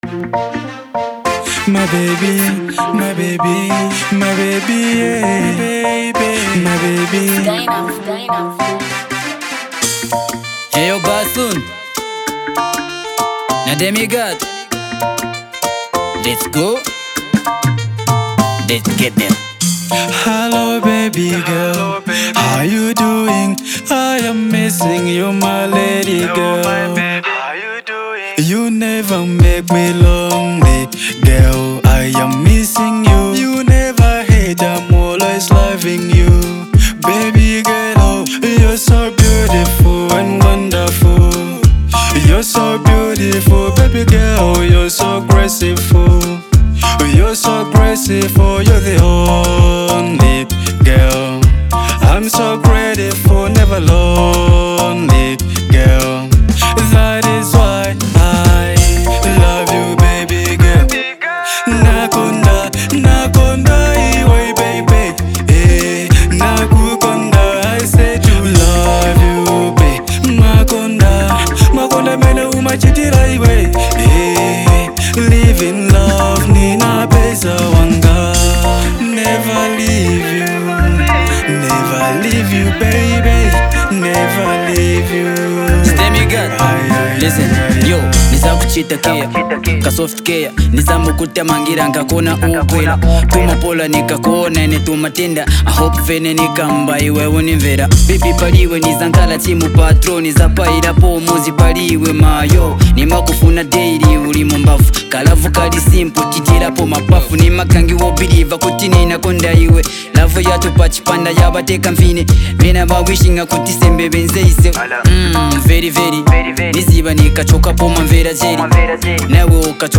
a touching and soulful love song